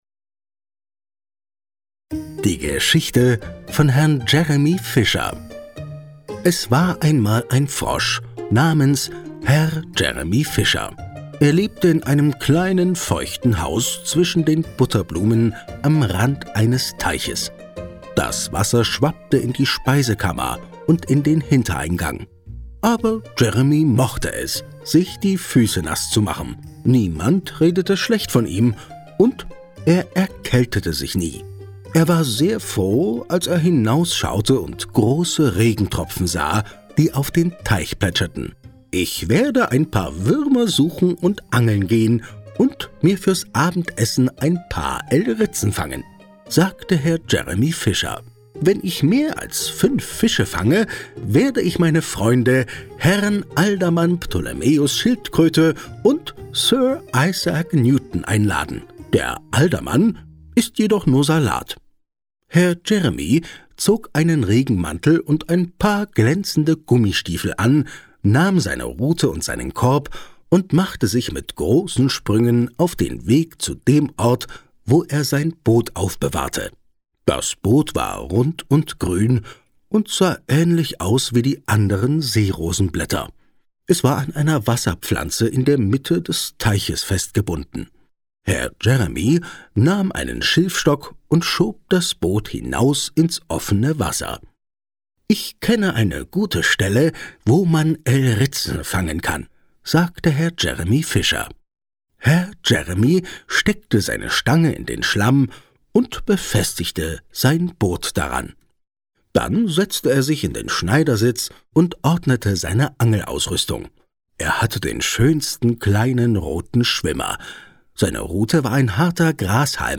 Lustiges Hörbuch zu Ostern
Ein Hörbuch mit den lustigen Geschichten von Beatrix Potter und Musik.
Dazu erklingt die klassische Musik von Johann Sebastian Bach.